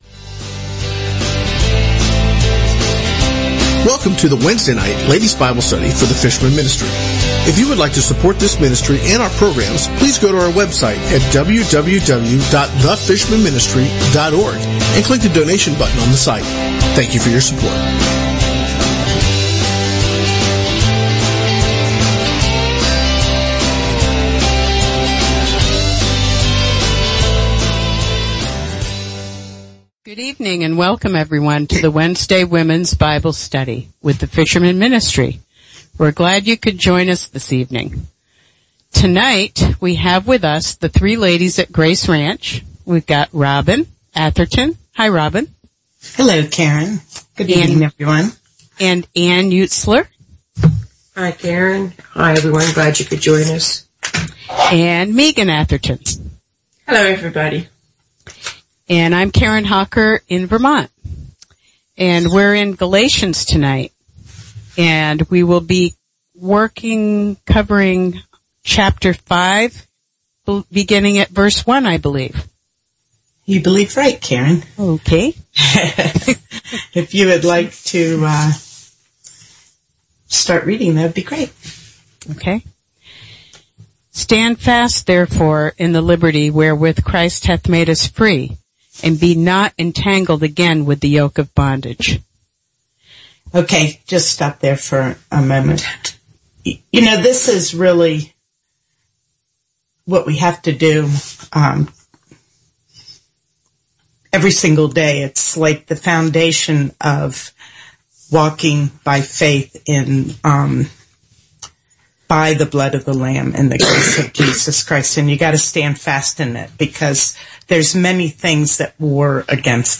Wednesday Womens Bible Study 05/25/2016 | The Fishermen Ministry